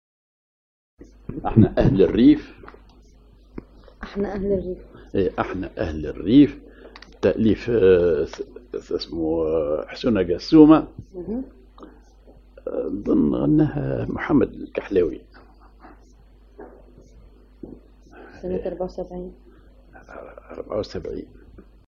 Maqam ar محير عراق
genre أغنية